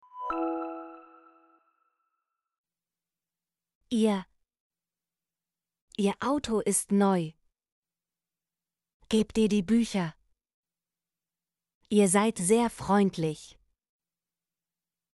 ihr - Example Sentences & Pronunciation, German Frequency List